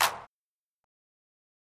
Rockin' Clap.wav